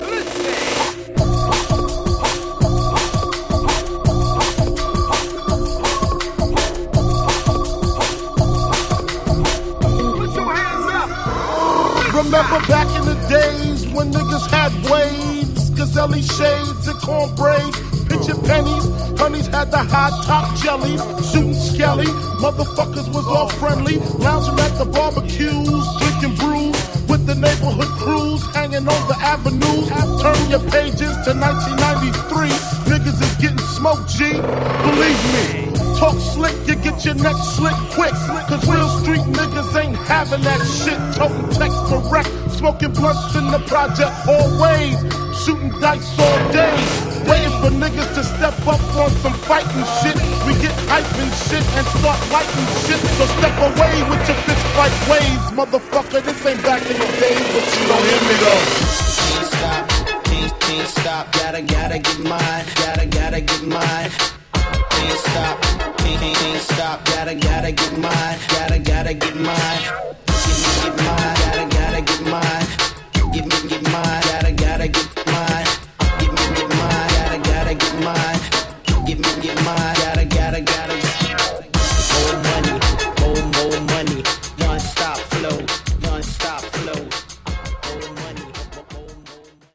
83 bpm
Dirty Version